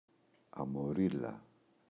αμορίλα, η [amo’rila]